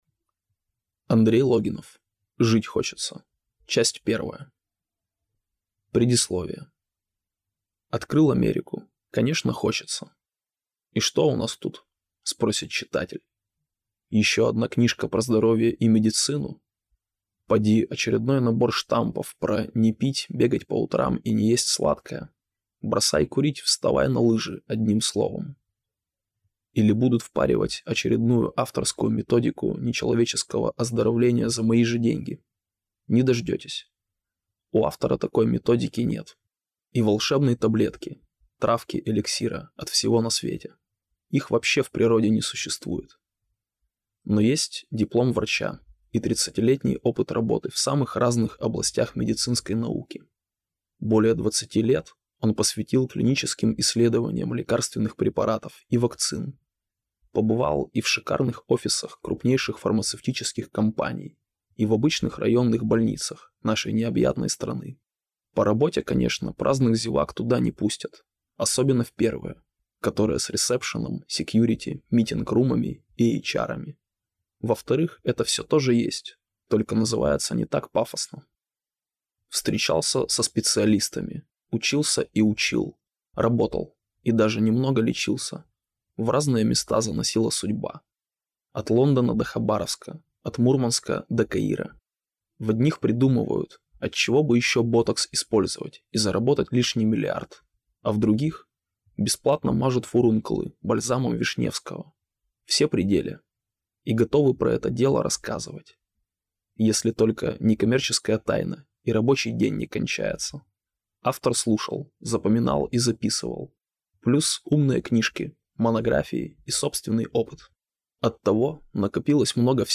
Аудиокнига Жить – хочется. Часть первая | Библиотека аудиокниг